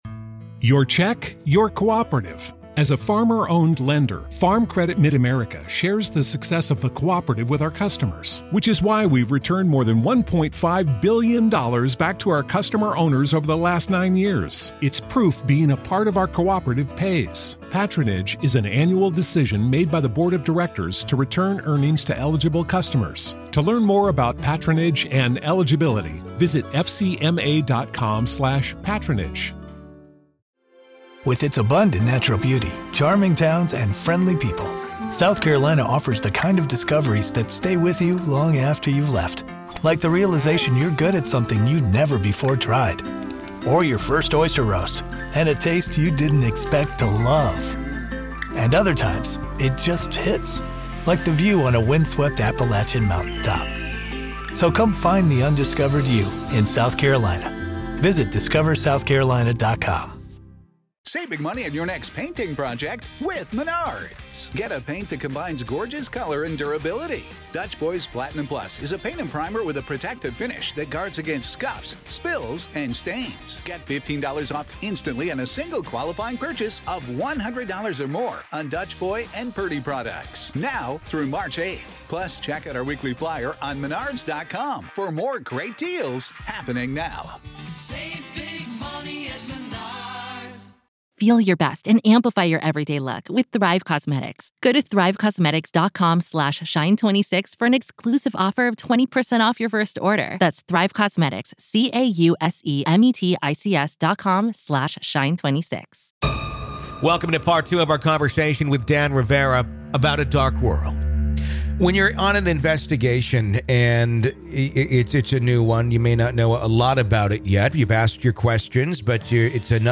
Together, we delve into demons, possessions, and the unsettling path the world seems to be on, all while learning how each of us can fortify ourselves against the dangers of the demonic realm. This is Part Two of our conversation.